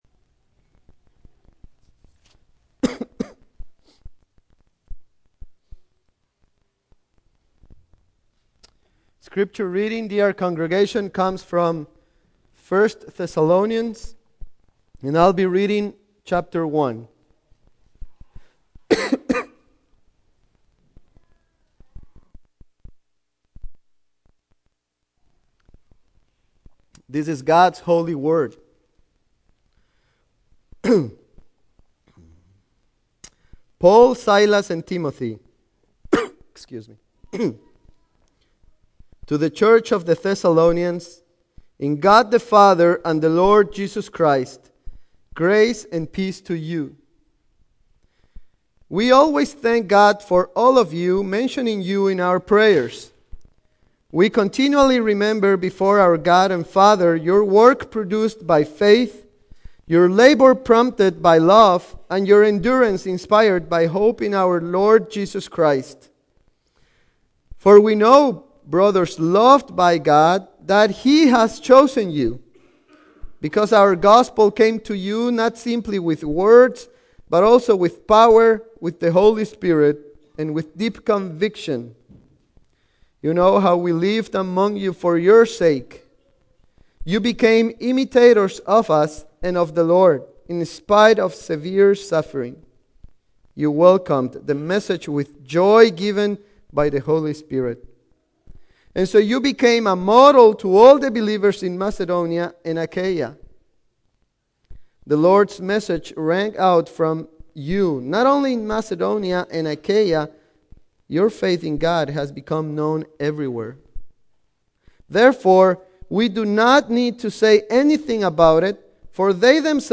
Single Sermons Passage: 1 Thessalonians 1:1-10 %todo_render% « The Good News of God’s Providence Our One Mediator